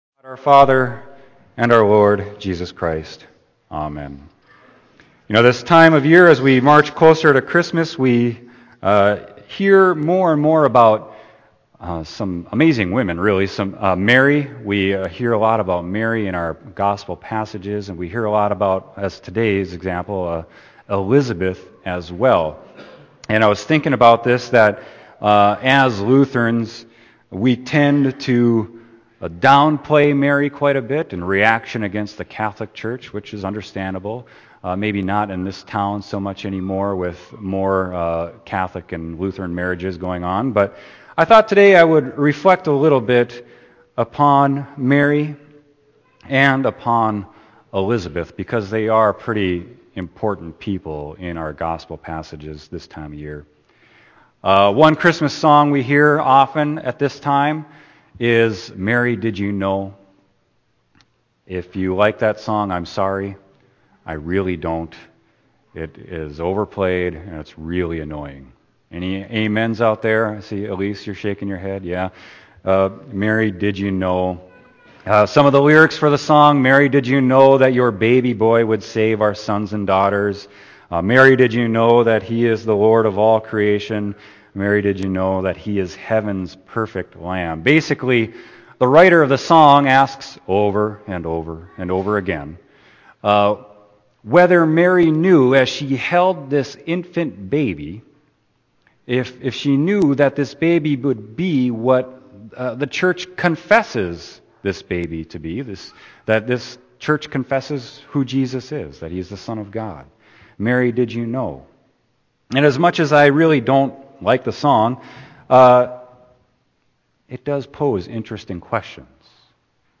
Sermon: Luke 1.39-45